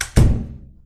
DoorCloseSoundEffect.wav